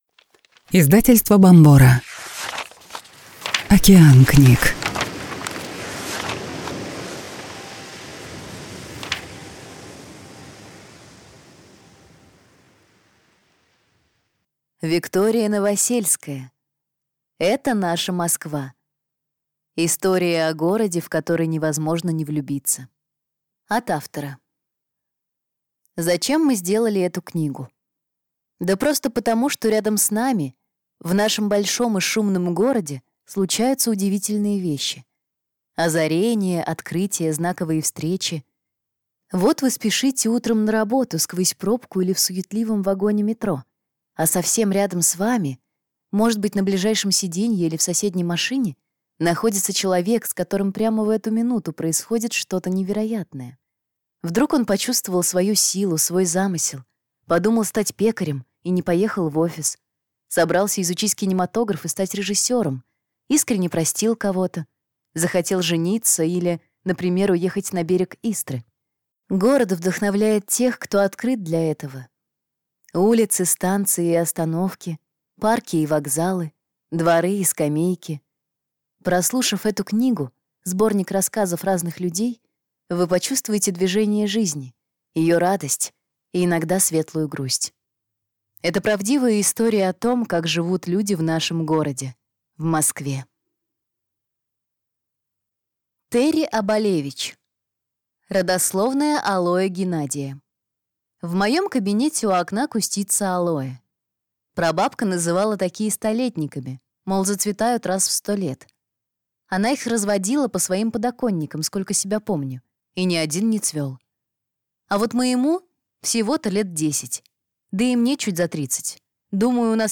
Аудиокнига Это наша Москва. Истории о городе, в который невозможно не влюбиться | Библиотека аудиокниг